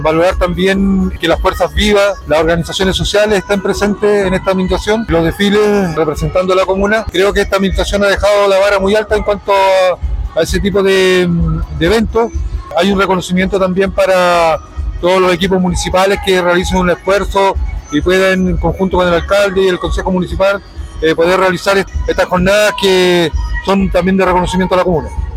David Muñoz, Core de la provincia del Choapa entregó sus impresiones de esta ceremonia